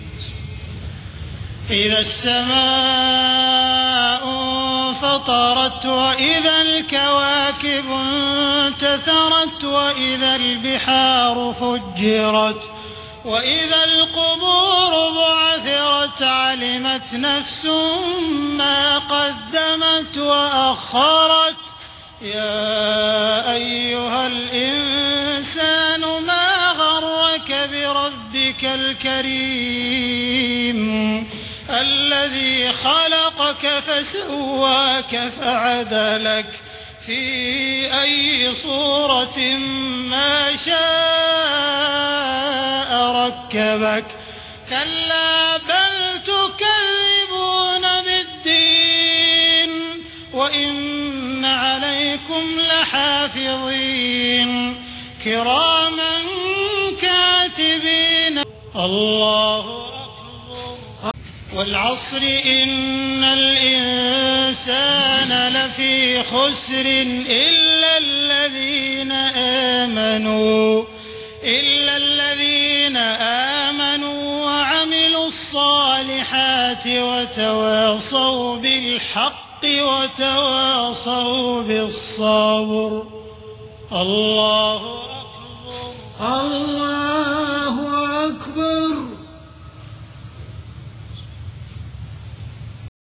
صلاة المغرب 21 محرم 1430هـ سورتي الانفطار والعصر ناقصة > 1430 🕋 > الفروض - تلاوات الحرمين